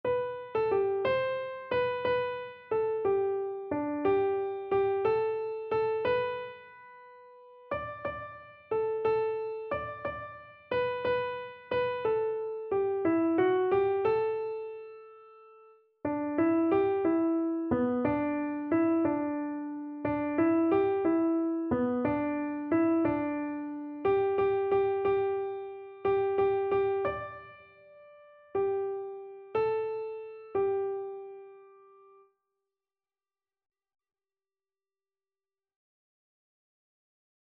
Free Sheet music for Keyboard (Melody and Chords)
G major (Sounding Pitch) (View more G major Music for Keyboard )
6/8 (View more 6/8 Music)
Keyboard  (View more Easy Keyboard Music)
Classical (View more Classical Keyboard Music)